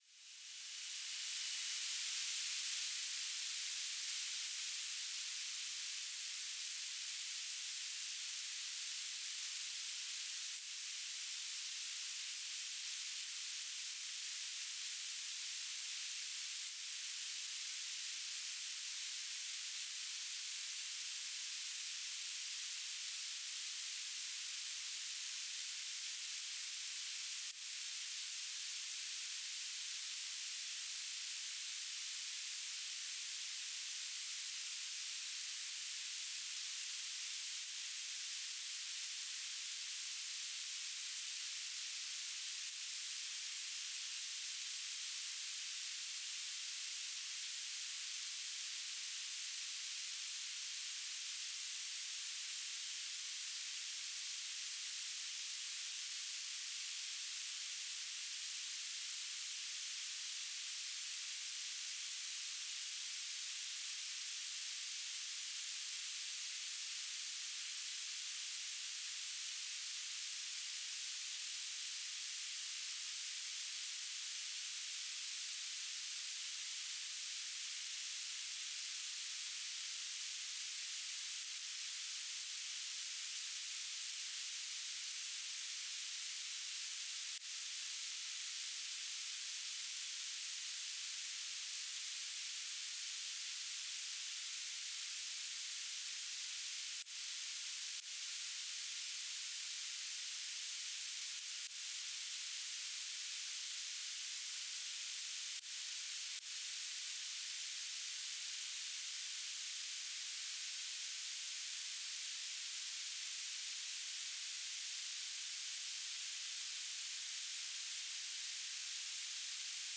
"transmitter_description": "Beacon",
"transmitter_mode": "BPSK",